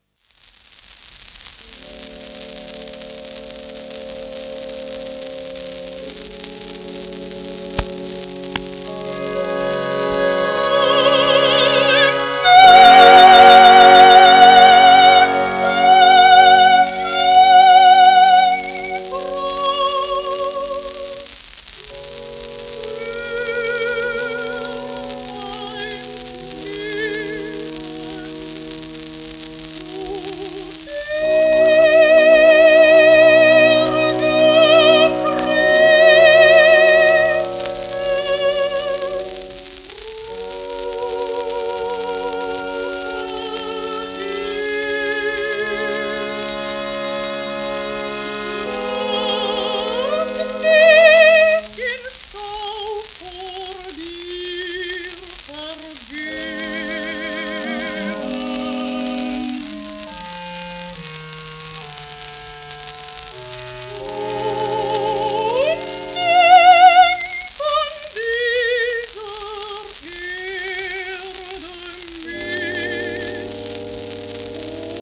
w/オーケストラ
盤質A- *キズ[2回程度のクリック音]